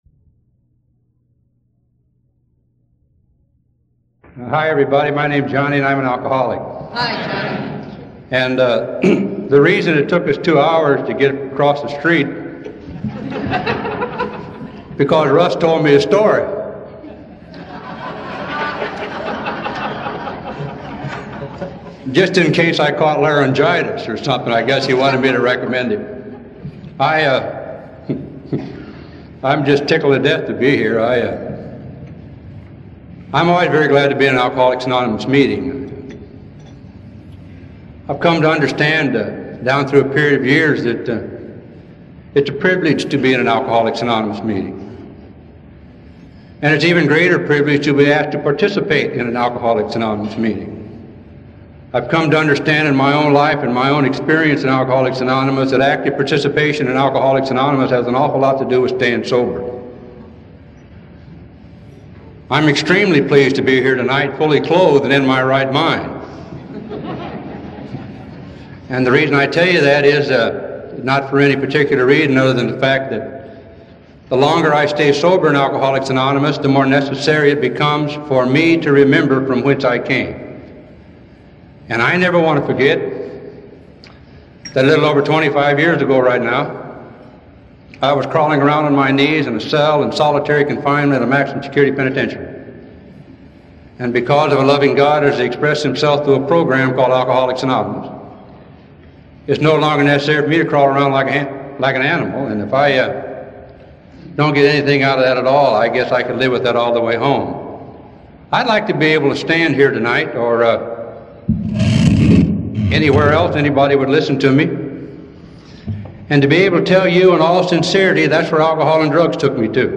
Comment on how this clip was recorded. the 28th Miami Dade Intergroup Banquet, Miami Florida, 2018